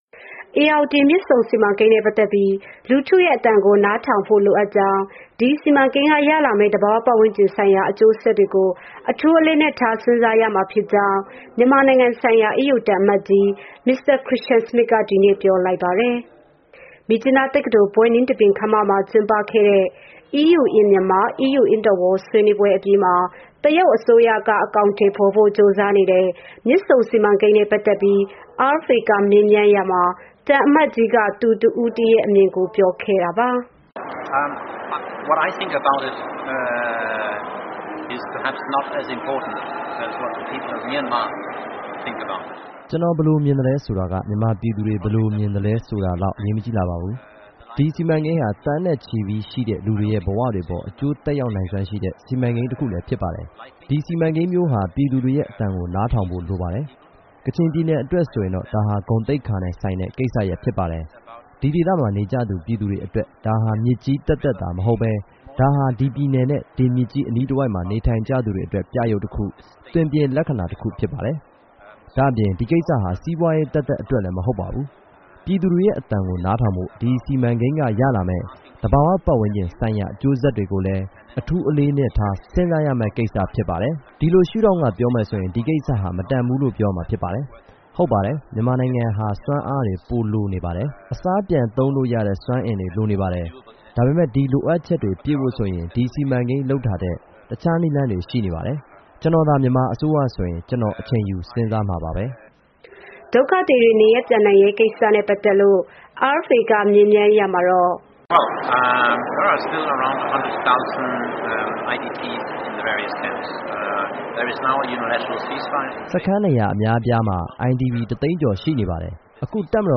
မြစ်ကြီးနားတက္ကသိုလ် ဘွဲ့နှင်းသဘင်ခန်းမမှာ ကျင်းပခဲ့တဲ့ EU in Myanmar , EU in the World ဆွေးနွေးပွဲ အပြီးမှာ တရုတ်အစိုးရက အကောင်အထည်ဖော်ဖို့ ကြိုးစားနေတဲ့ မြစ်ဆုံစီမံကိန်းနဲ့ ပတ်သက်ပြီး RFA က မေးမြန်းရာမှာ သံအမတ်ကြီးက သူတစ်ဦးတည်းရဲ့အမြင်ကို ပြောခဲ့တာပါ။